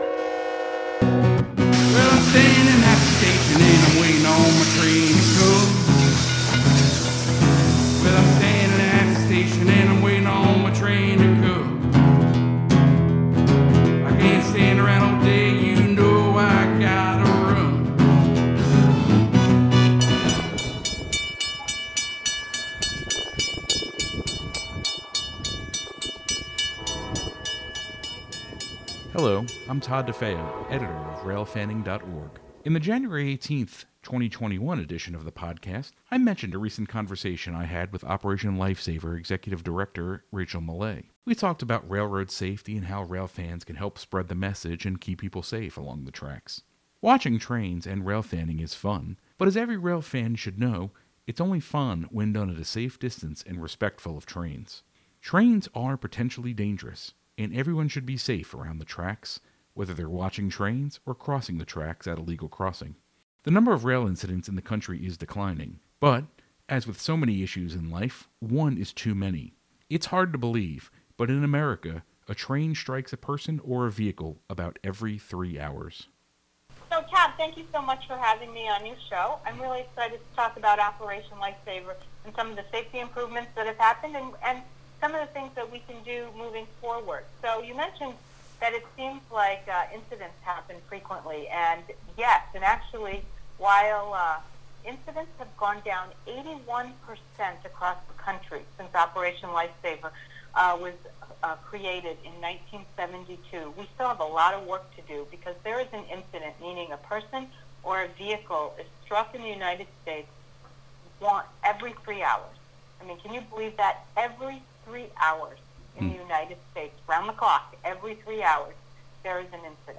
Railfanning Review: A Conversation with Operation Lifesaver